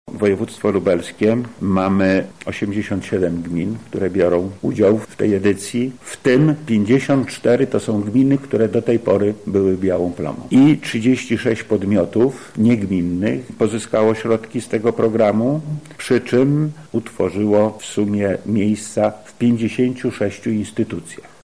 -mówi Lech Sprawka, Wojewoda Lubelski.